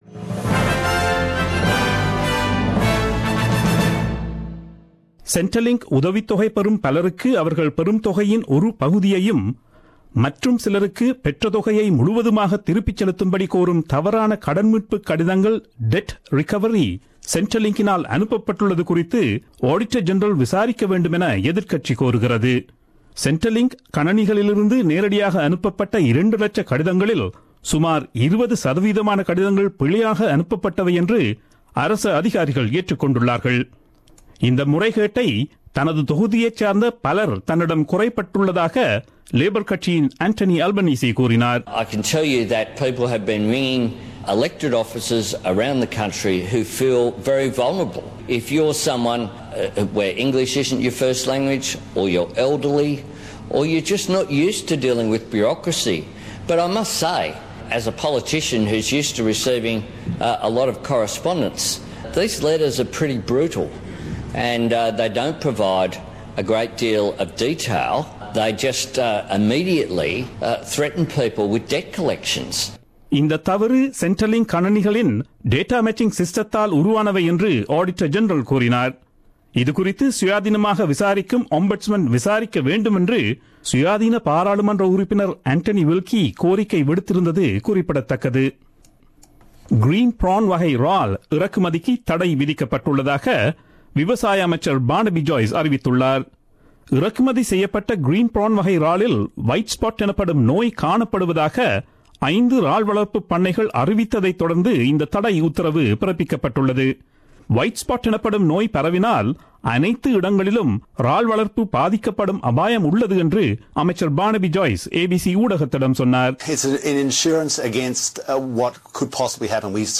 Australian news bulletin aired on Friday 6 Jan 2017 at 8pm.